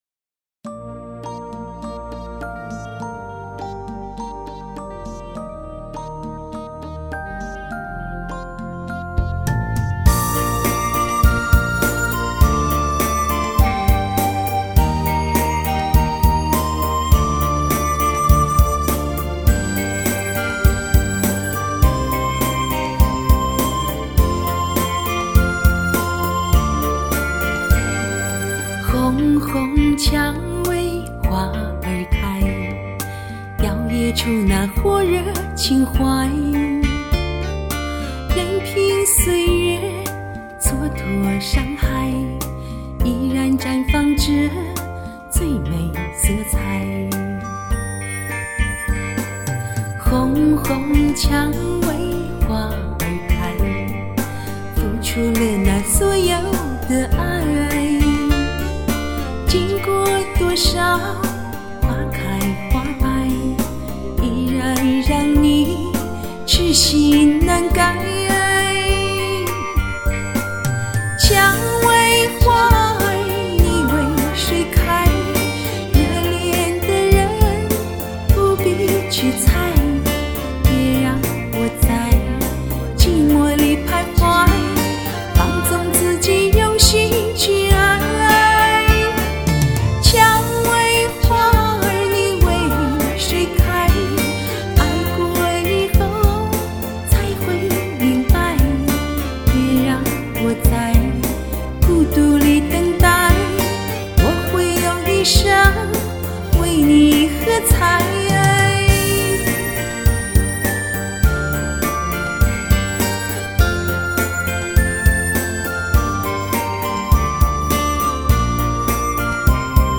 她的音色柔和甜美